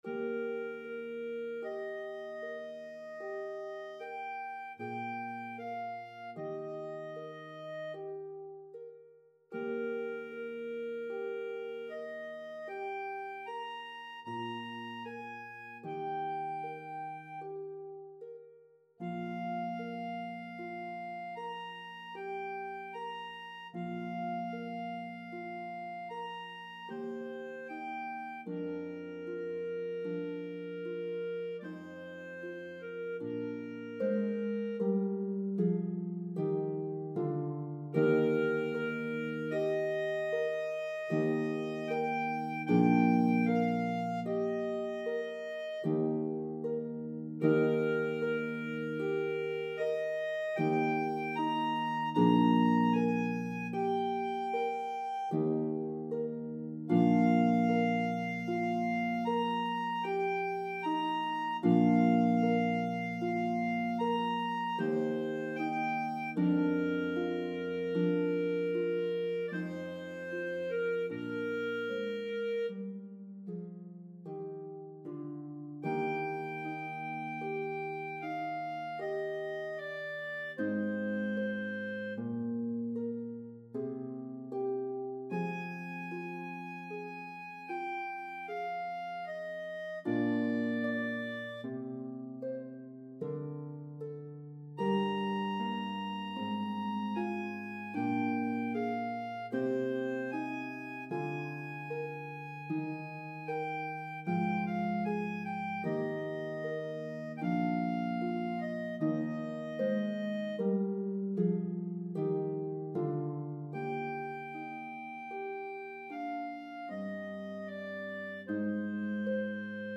The orchestra parts play beautifully on a pedal harp.